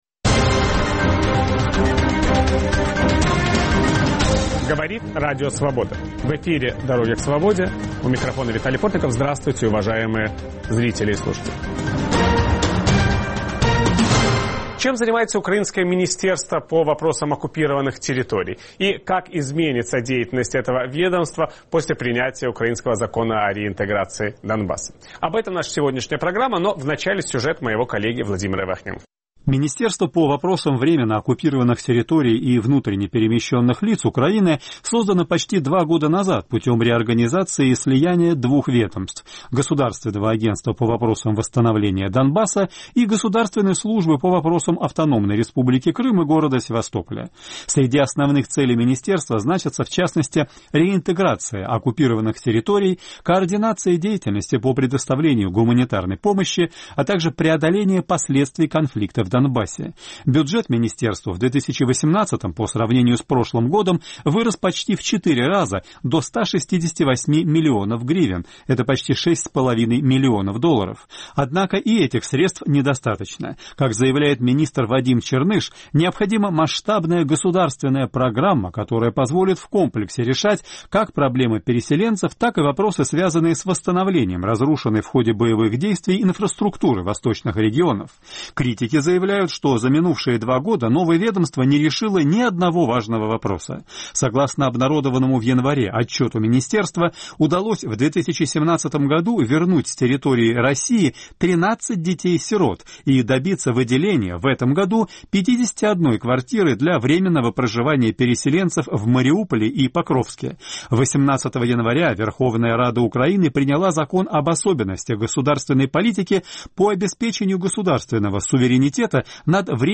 Заместитель украинского министра по вопросам временно оккупированных территорий Юрий Грымчак рассказывает Виталию Портникову, чем занимается его ведомство и можно ли рассчитывать на решение конфликта.